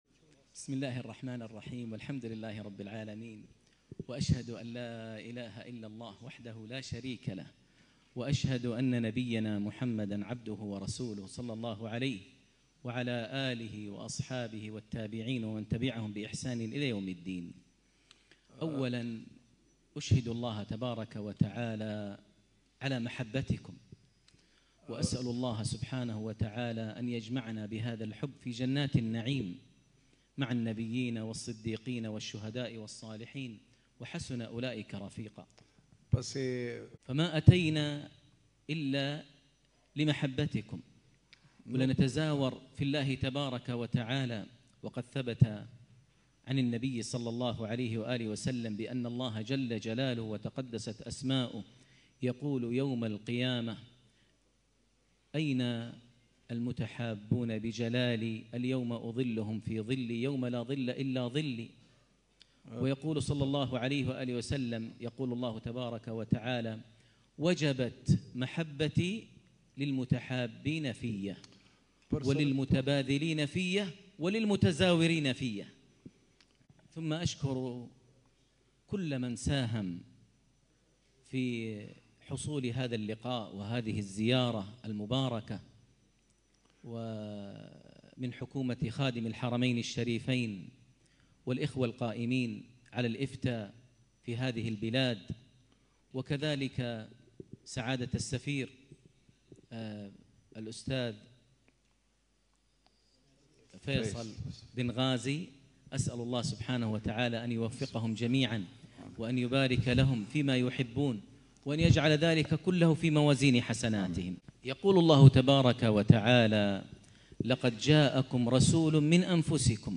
كلمة بعنوان ﴿حَريصٌ عَلَيكُم﴾ بعد صلاة المغرب | 15 جمادى الآخرة 1447هـ > زيارة الشيخ د. ماهر المعيقلي إلى مقدونيا الشمالية 1447هـ > تلاوات و جهود الشيخ ماهر المعيقلي > المزيد - تلاوات الحرمين